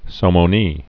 (sōmō-nē)